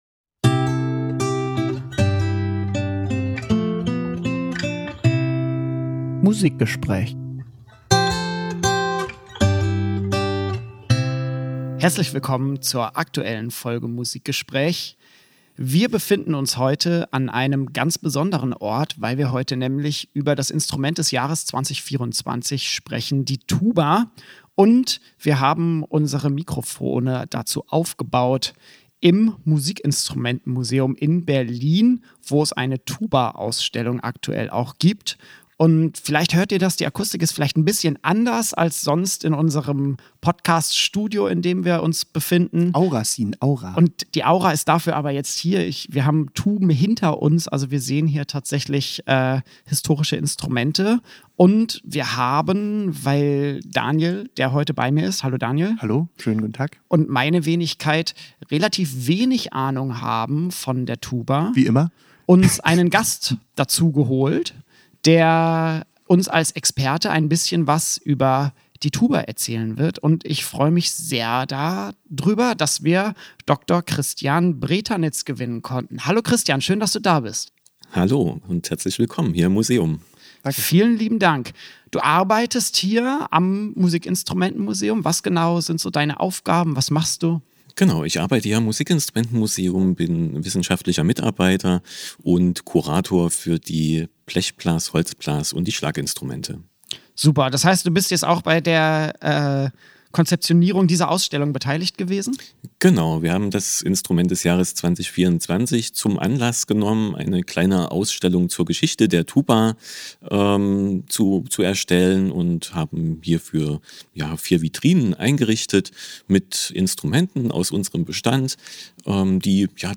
Musikgespräch ist zu Gast im Musikinstrumentenmuseum Berlin.